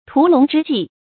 屠龙之伎 tú lóng zhī jì
屠龙之伎发音